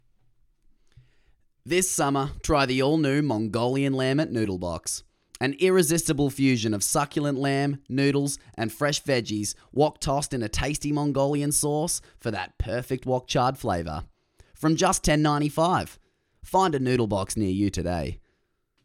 Male
English (Australian)
Yng Adult (18-29), Adult (30-50)
Television Spots
Food Commercial